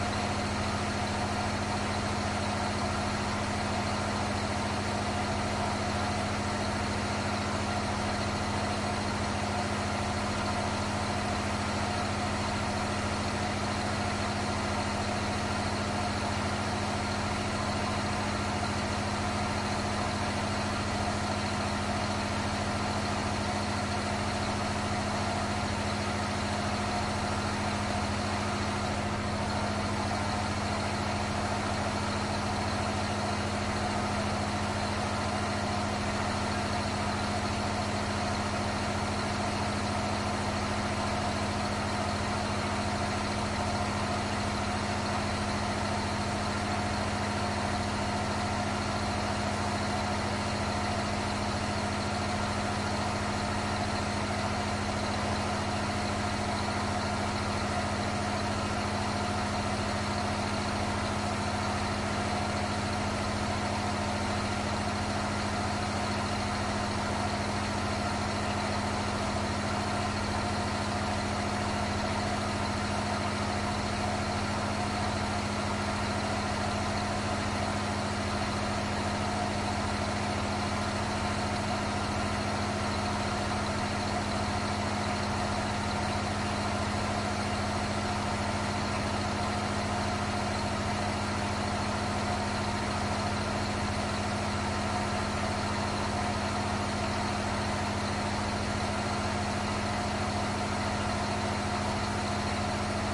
亚马逊 " 船亚马逊渡轮2层柴油驳船上的大声4发动机
描述：船亚马逊渡轮2甲板柴油驳船车载大声引擎
Tag: 驳船 柴油 机载 轮渡